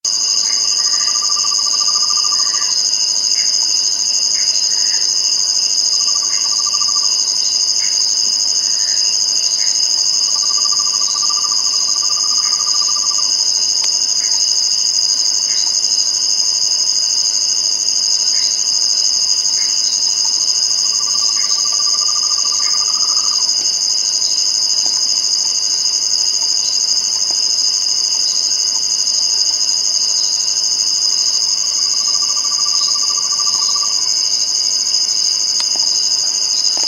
Buff-fronted Owl (Aegolius harrisii)
Life Stage: Adult
Location or protected area: Parque Nacional Chaco
Condition: Wild
Certainty: Observed, Recorded vocal